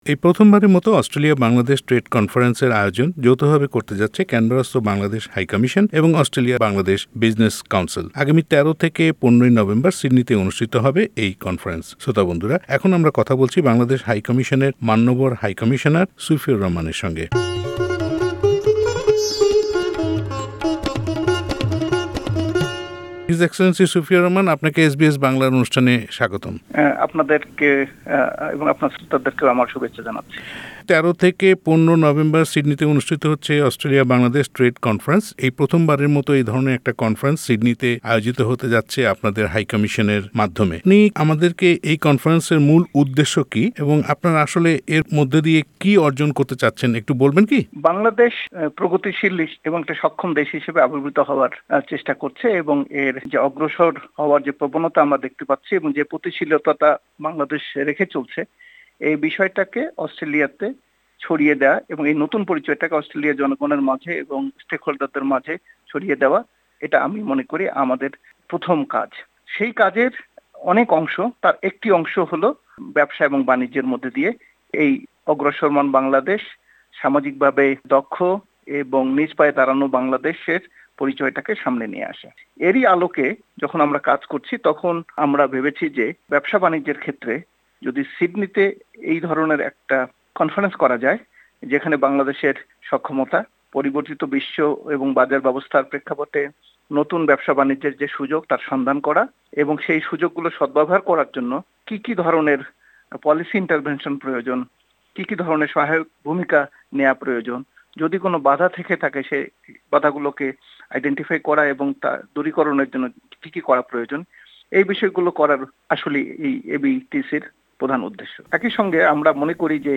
এ সম্পর্কে এসবিএস বাংলার সঙ্গে কথা বলেছেন অস্ট্রেলিয়ায় নিযুক্ত বাংলাদেশের হাই কমিশনার মান্যবর মোহাম্মদ সুফিউর রহমান।